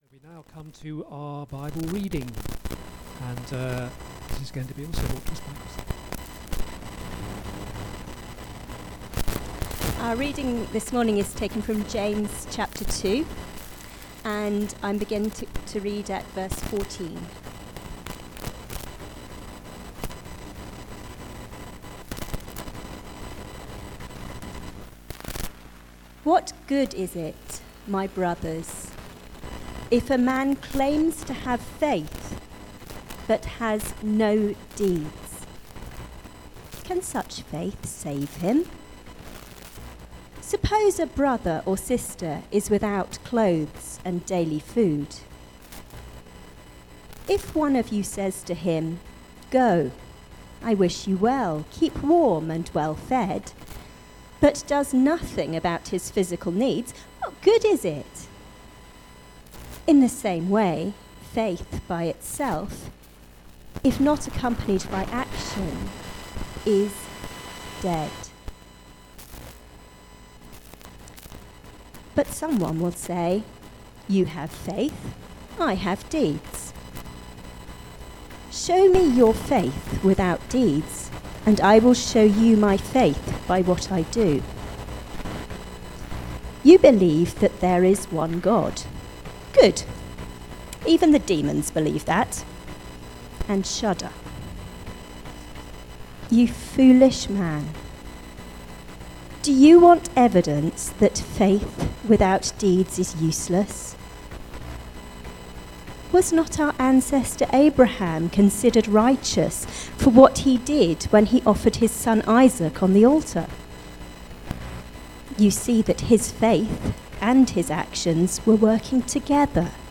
The reading is James 2:14-26.